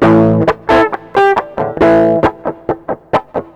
HARDREGGAE.wav